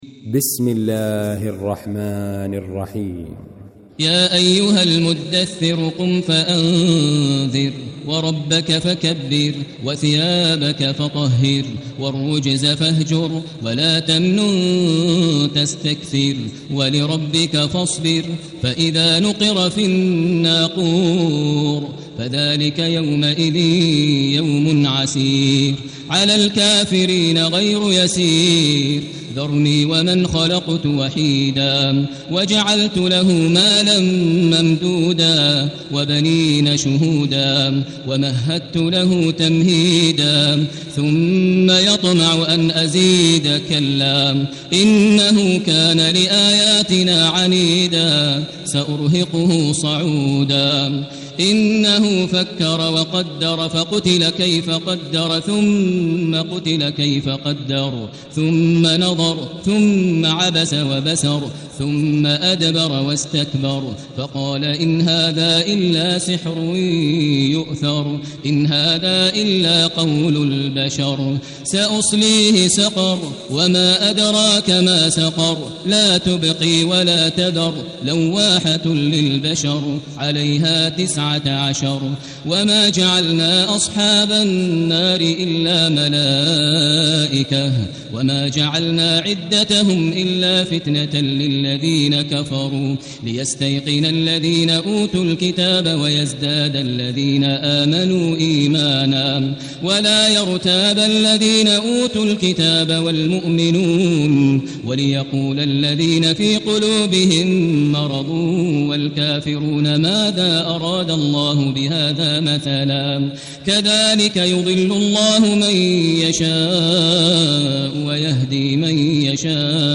المكان: المسجد الحرام الشيخ: فضيلة الشيخ ماهر المعيقلي فضيلة الشيخ ماهر المعيقلي المدثر